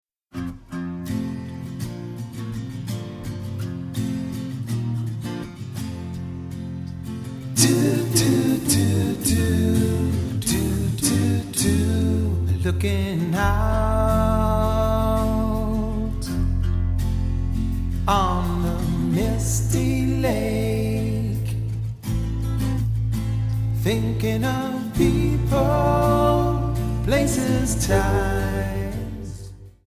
Alternative,Blues